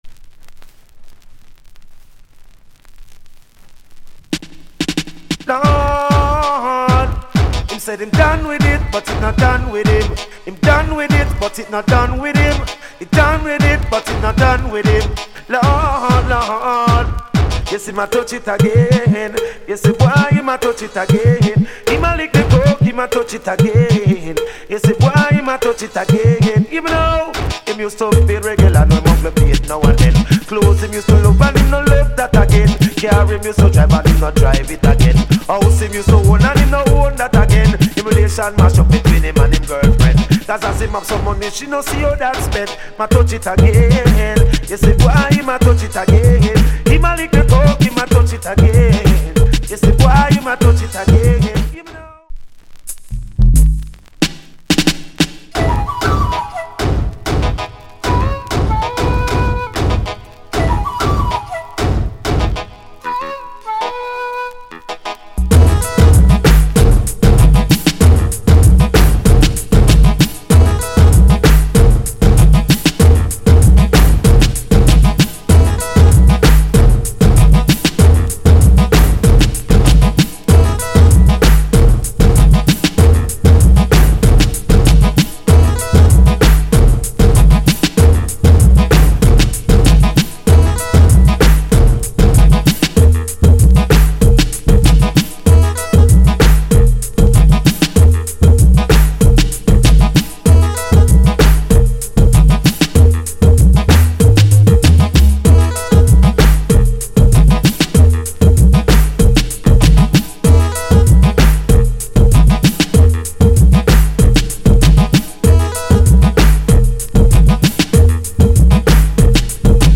Male Vocal Condition EX- Soundclip